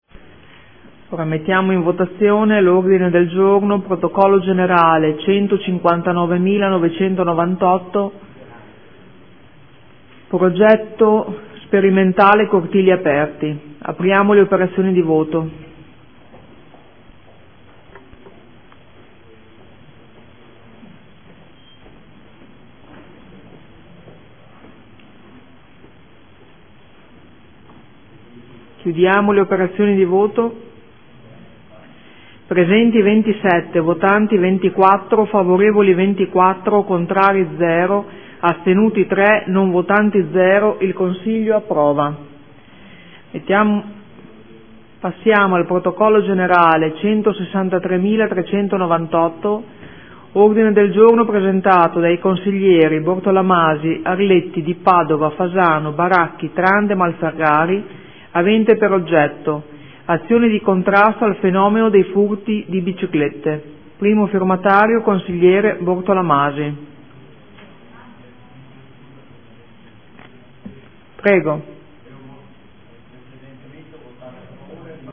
Presidente — Sito Audio Consiglio Comunale
Presidente